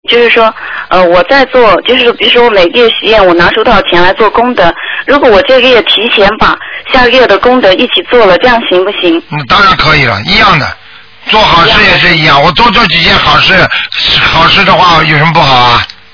目录：剪辑电台节目录音_集锦